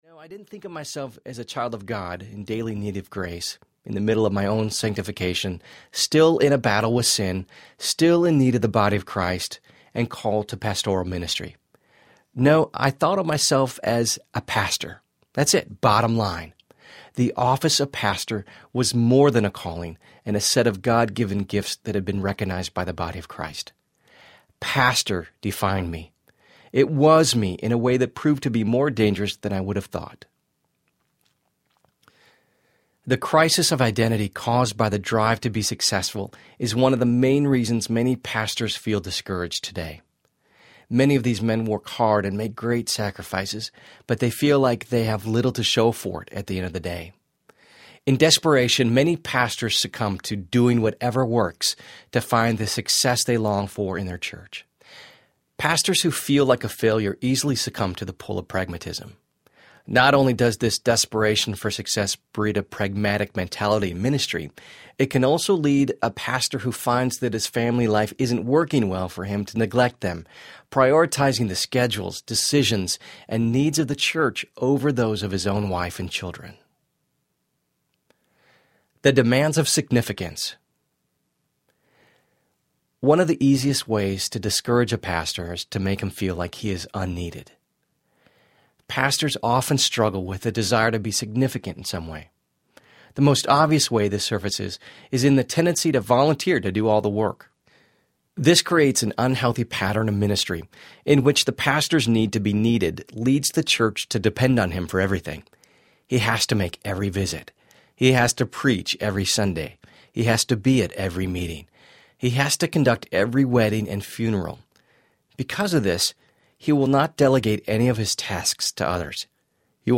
The Pastor’s Family Audiobook